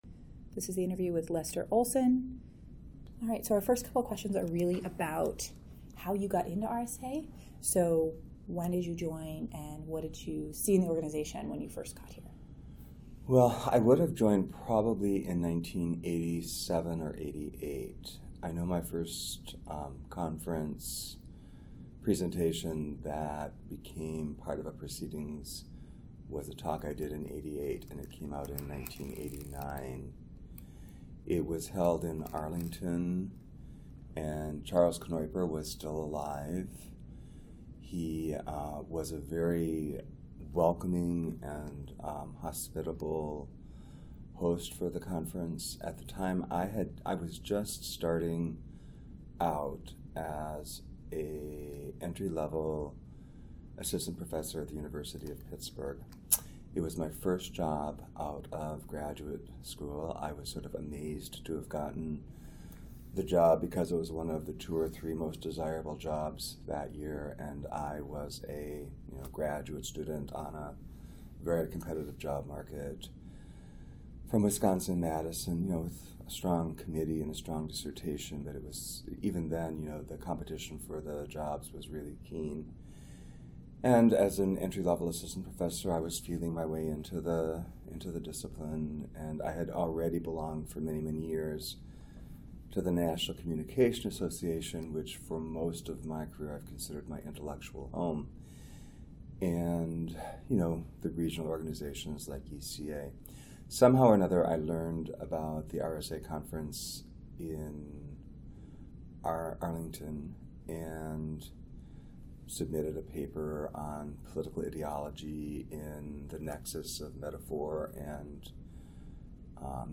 Oral History
Location 2018 RSA Conference in Minneapolis, Minnesota